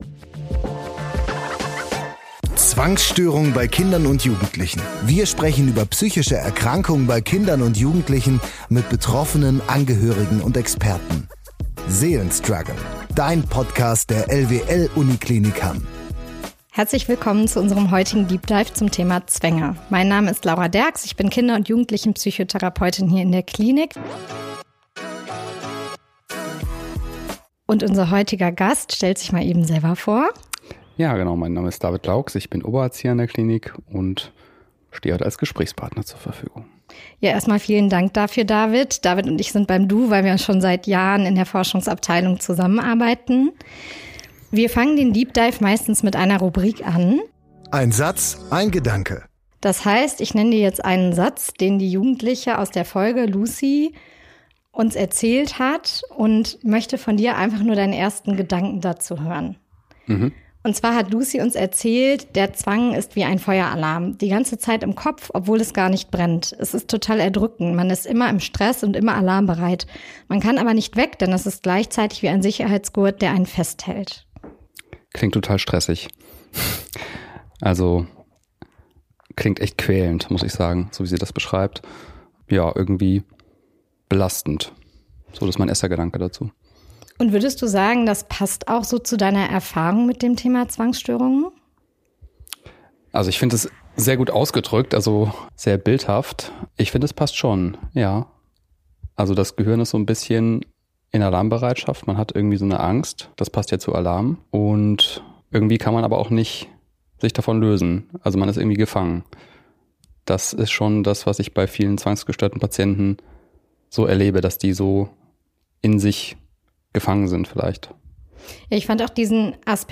Der Experte gibt uns wertvolle Insights, wie es gelingen kann, aus der Gedankenspirale rauszukommen, den inneren Druck zu verringern und mehr Kontrolle über die eigenen Gedanken zurückzugewinnen. Ein spannendes Gespräch, das dir hilft, Zwangsstörungen besser zu verstehen.